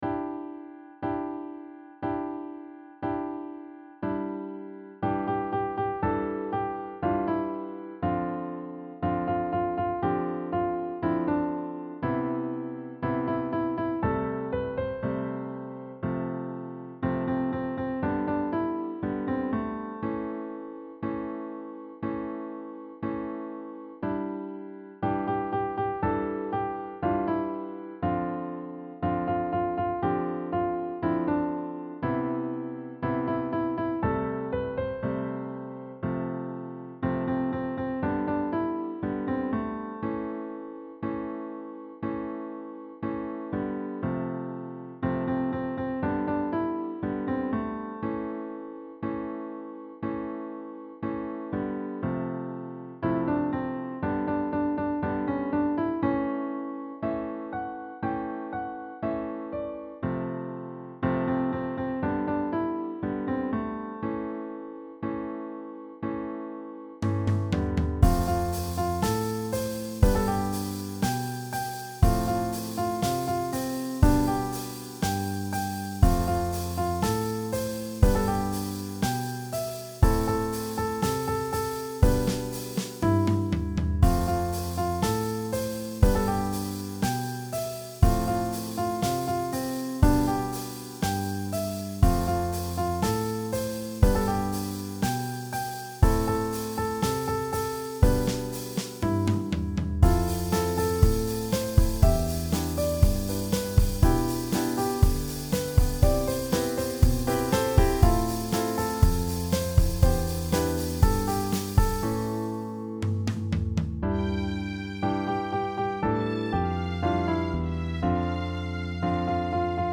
AcousticBallad
이건 정말 힐링송이네요.